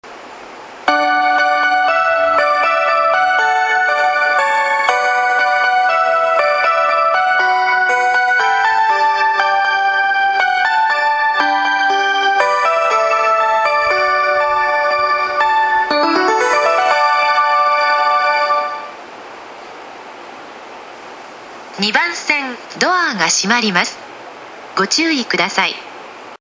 ２番線常磐線
発車メロディーフルコーラスです。